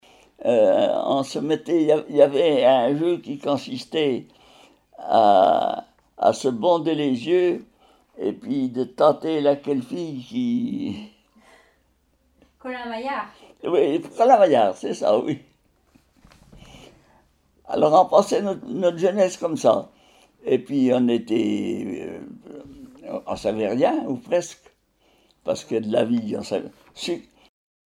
Enquête Arexcpo en Vendée-Pays Sud-Vendée
Catégorie Témoignage